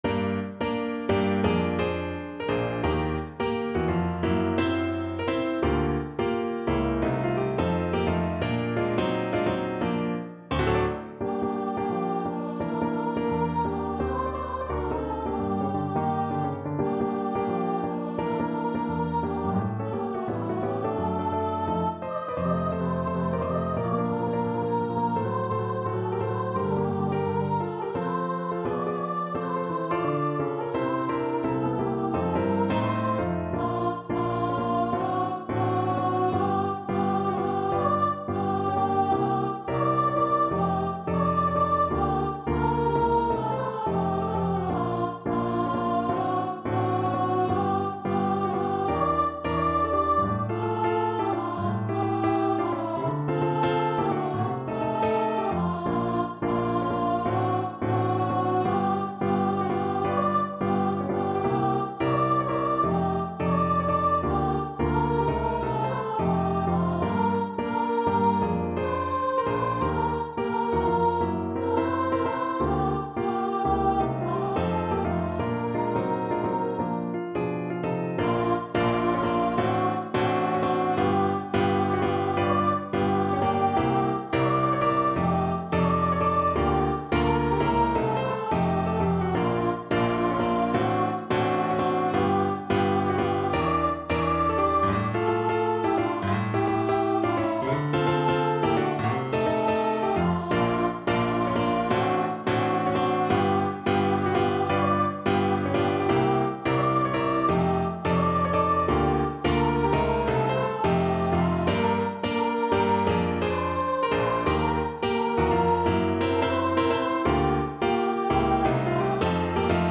Animato =86
2/2 (View more 2/2 Music)
Jazz (View more Jazz Voice Music)